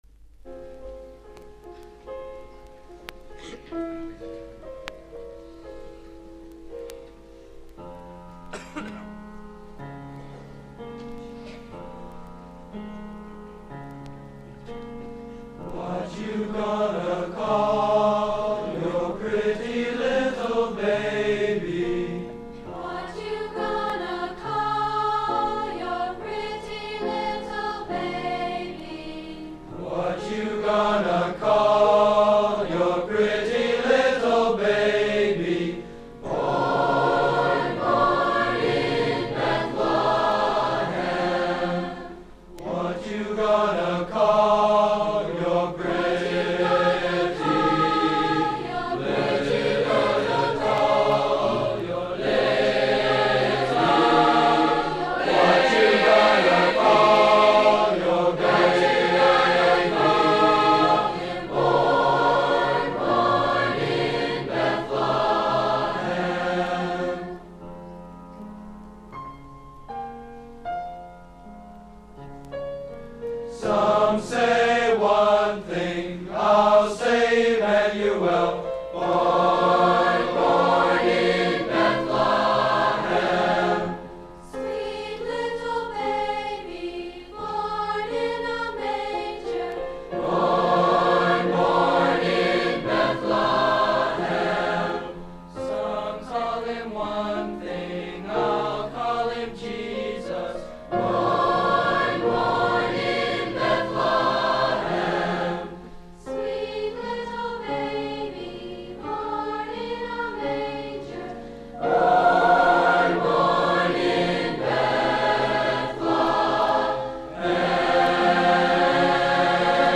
Recordings of public performances of the Music Department
Annual Christmas Concert, 21 December 1966
What You Gonna Call Yo' Pretty Little Baby (Negro spiritual) — The Mixed Chorus — 3:08